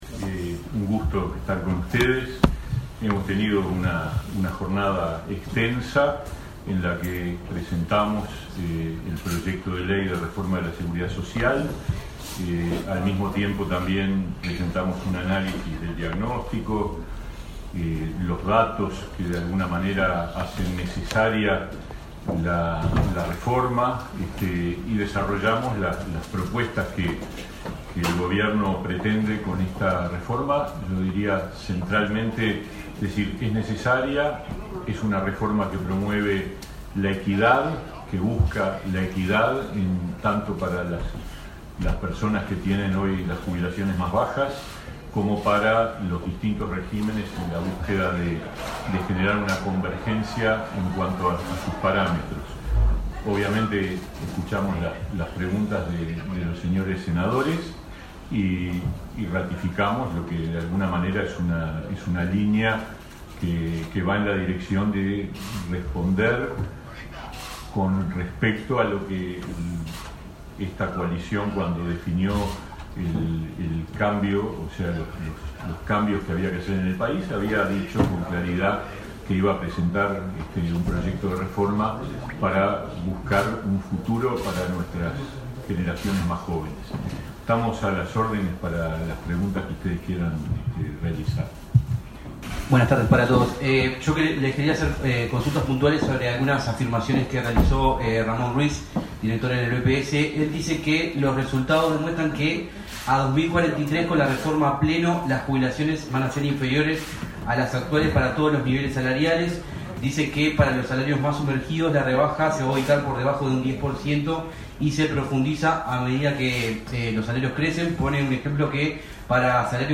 Conferencia de prensa de autoridades del Gobierno tras presentar el proyecto de reforma de seguridad social en el Parlamento
Participaron el ministro de Trabajo y Seguridad Social, Pablo Mieres; el subsecretario de ese ministerio, Mario Arizti; el director de la Oficina de Planeamiento y Presupuesto, Isaac Alfie, y el presidente de la Comisión de Expertos en Seguridad Social, Rodolfo Saldain.
conferencia.mp3